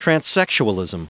Prononciation du mot transsexualism en anglais (fichier audio)
Prononciation du mot : transsexualism